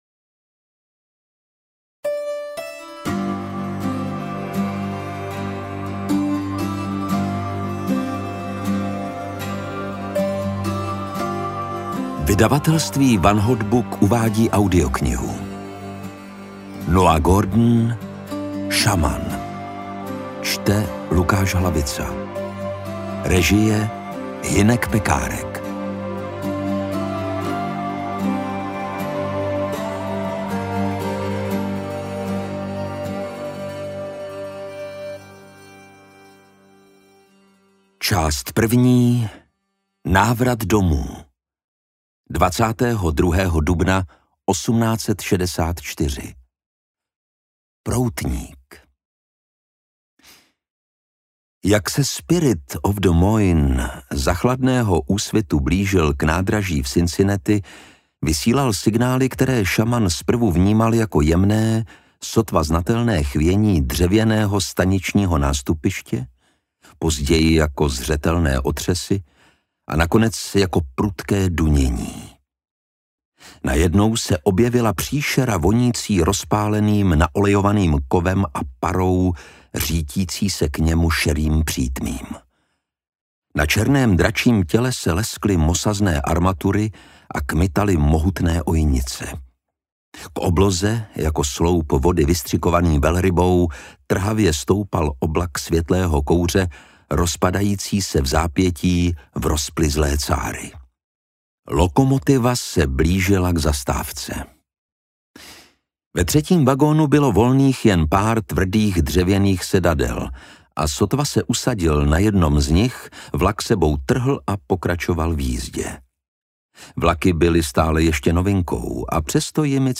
Interpret:  Lukáš Hlavica
AudioKniha ke stažení, 84 x mp3, délka 26 hod. 45 min., velikost 1440,0 MB, česky